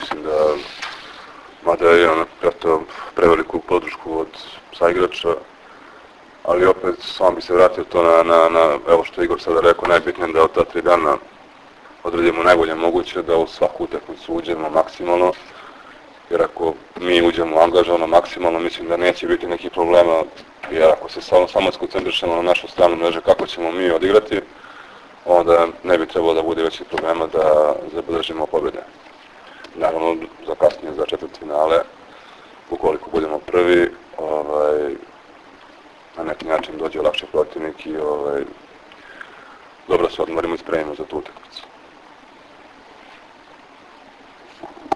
Tim povodom, danas je u beogradskom hotelu “M” održana konferencija za novinare, kojoj su prisustvovali Igor Kolaković, Ivan Miljković, Dragan Stanković i Vlado Petković.
IZJAVA VLADE PETKOVIĆA